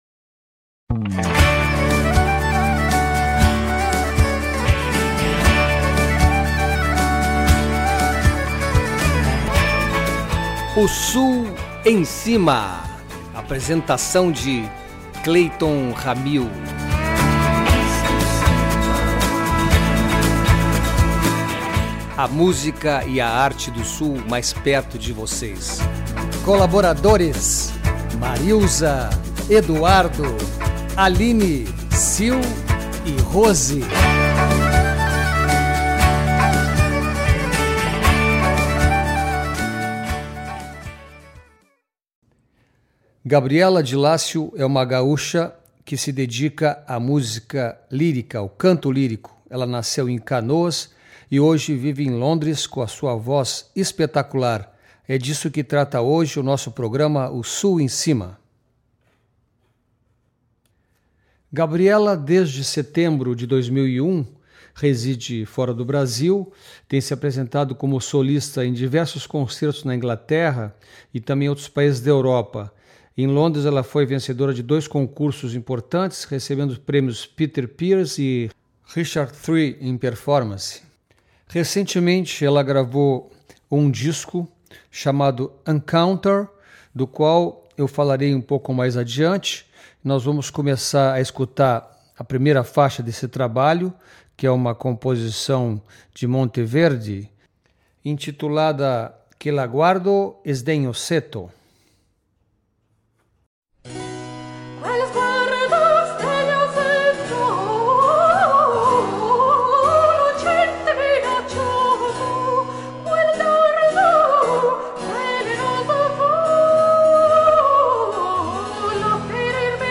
cantora lírica
Viol & Recorder
Theorbo & Baroque Guitar
Percussion